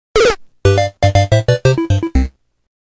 Copyrighted music sample